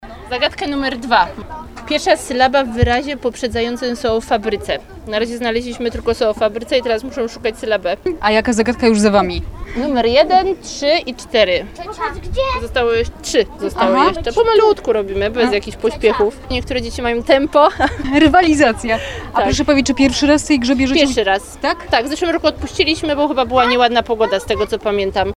– Ideą od samego początku, od kiedy została ta zabawa wymyślona, jest to, aby w ten dzień 1 czerwca – rodzice i dzieciaki, dzieciaki i rodzice – spędzili ze sobą trochę wolnego czasu – mówił nam Adam Ruśniak, zastępca prezydenta Bielska-Białej.